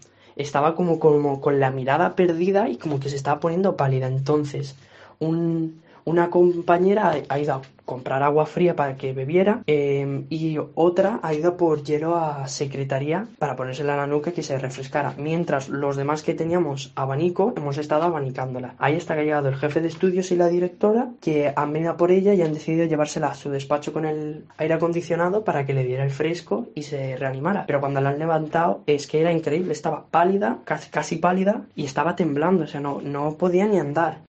Corte de voz de una de las profesoras